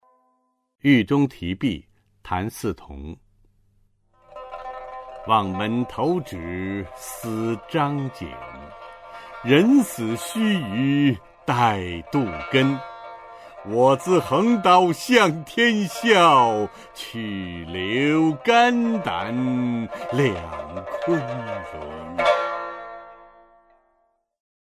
[清代诗词诵读]谭嗣同-狱中题壁 配乐诗朗诵